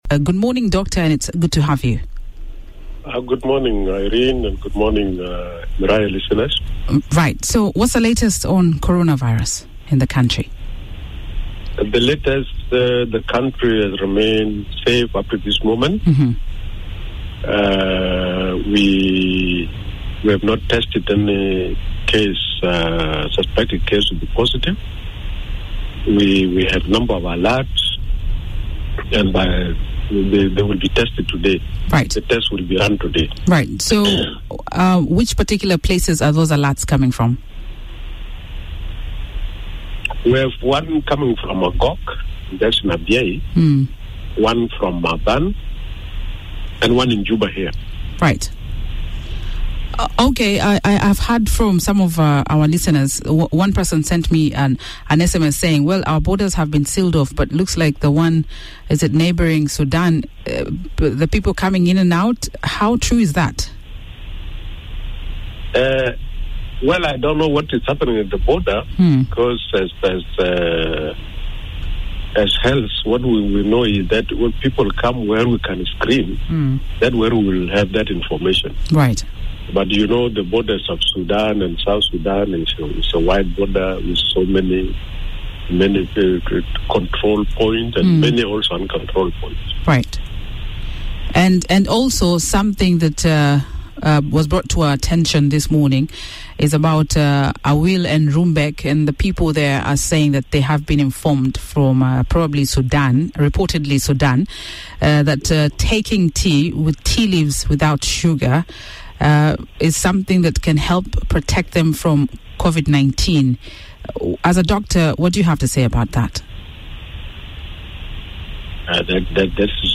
was speaking to Miraya breakfast this morning, take a listen.